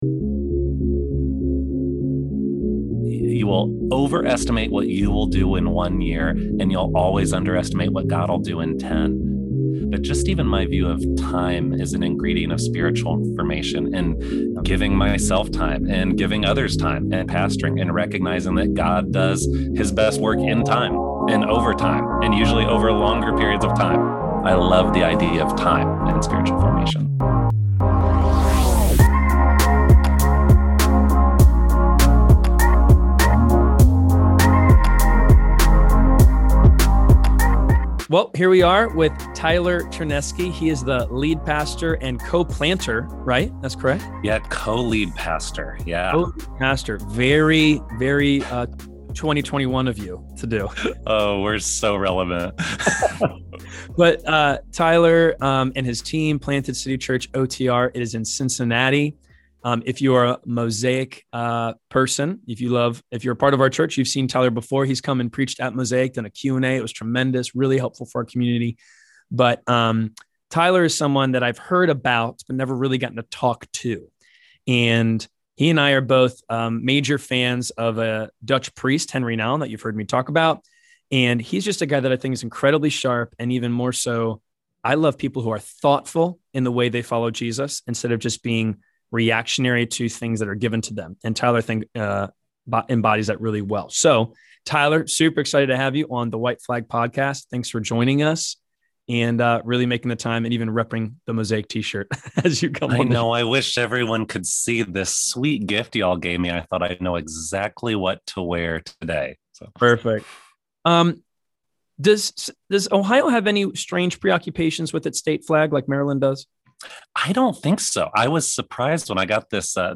6. Interview